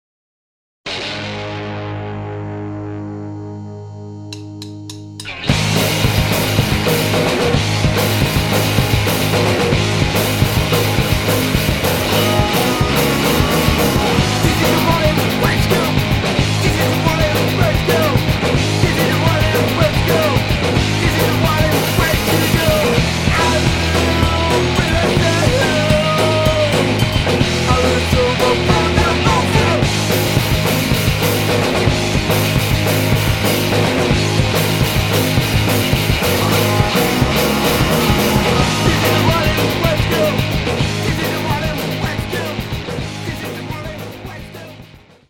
Power Pop